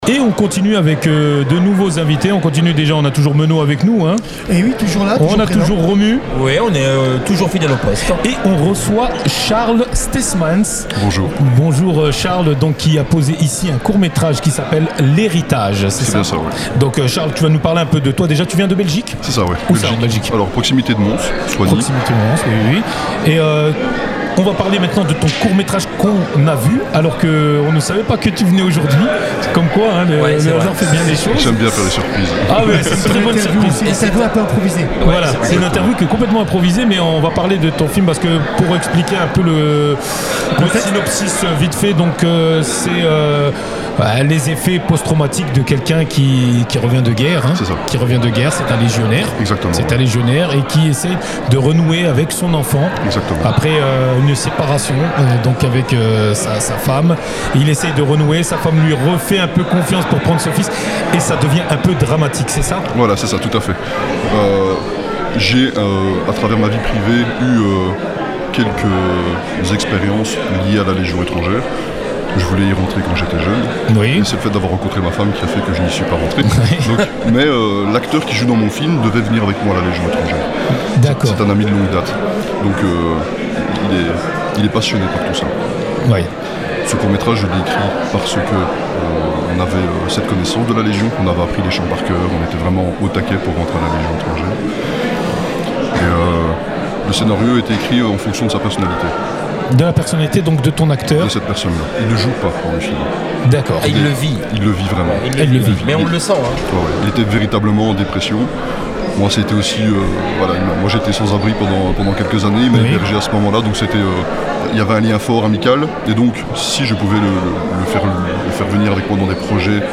Boîte à images (Interviews 2025)